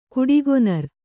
♪ kuḍigonar